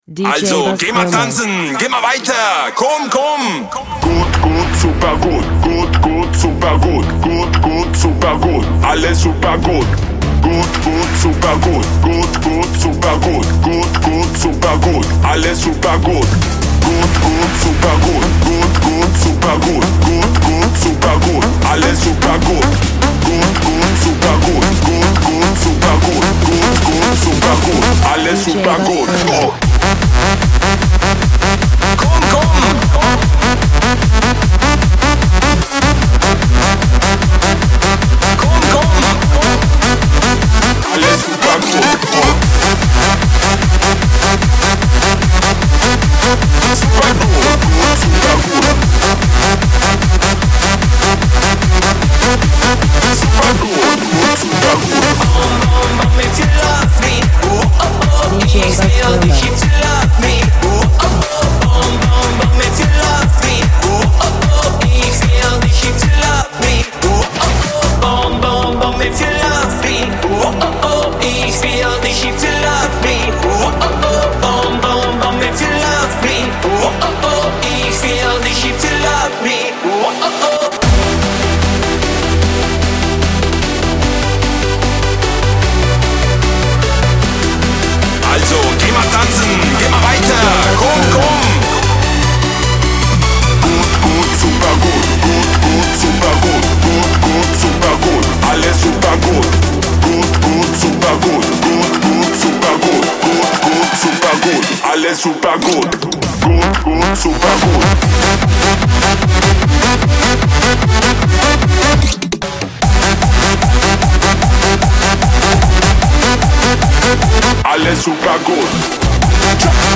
Eurodance
A powerful, fun, and energetic Electro Club remix